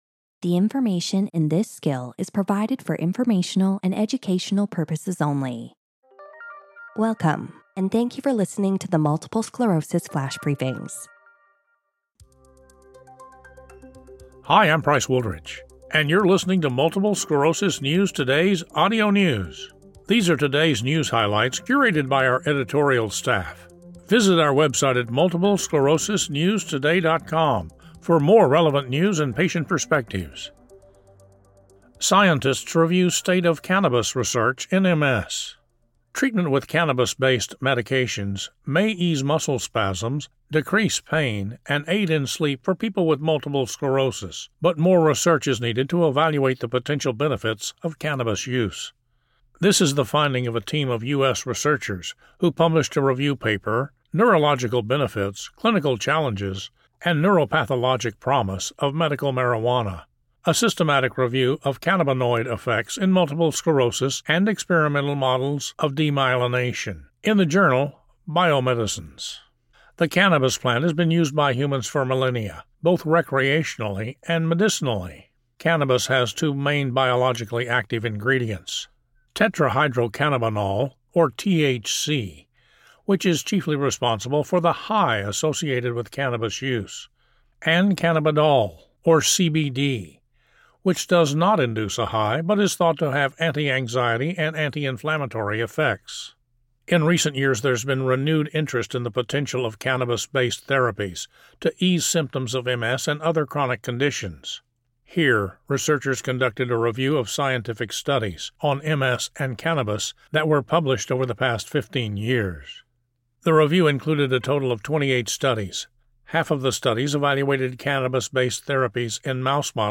reads an article about how cannabis-based medications could ease muscle spasms, decrease pain, and aid in sleep for people with MS.